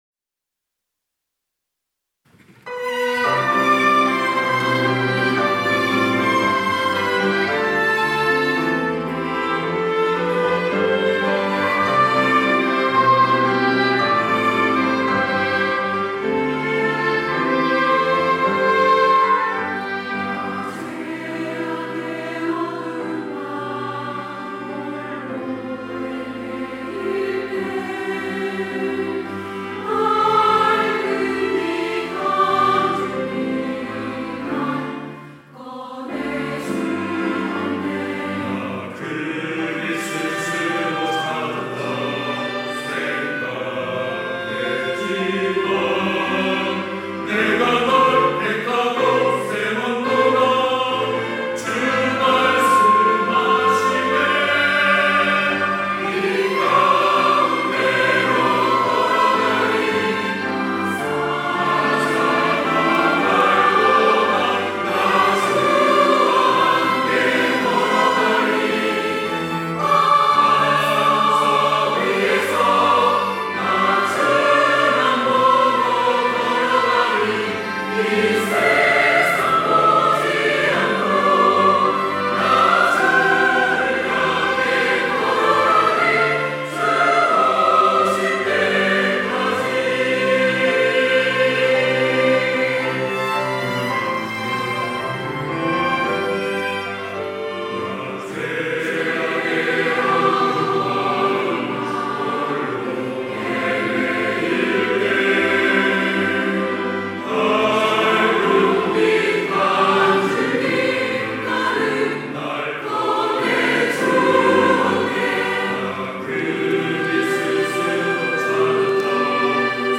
호산나(주일3부) - 주를 향해 걸어가리
찬양대